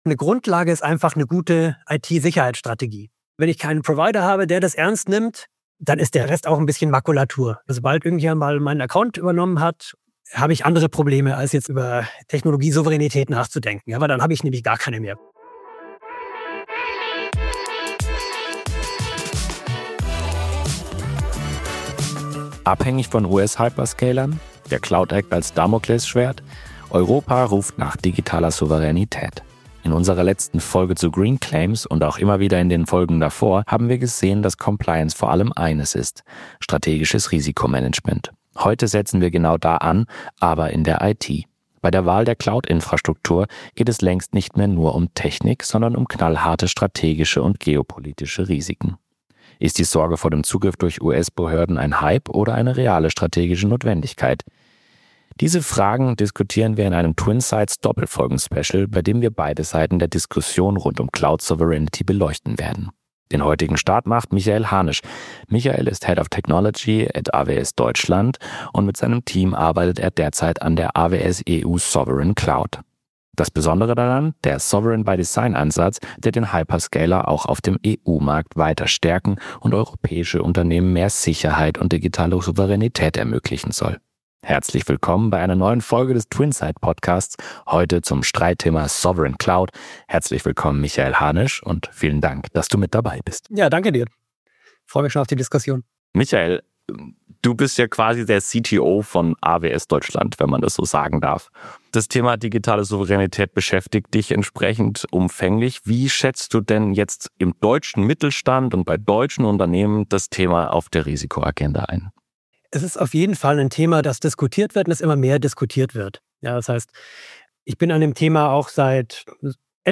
#33 - Sovereign Cloud - Wie viel Digitale Souveränität braucht es wirklich? Im Gespräch